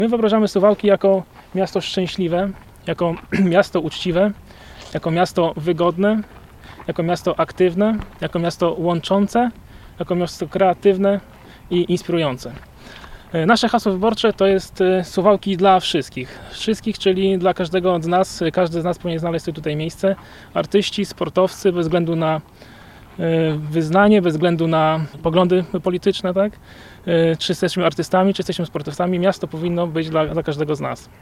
Swoje postulaty kandydat przedstawił na konferencji prasowej na wyspie na zalewie Arkadia wśród kandydatów Koalicji Obywatelskiej do Rady Miejskiej.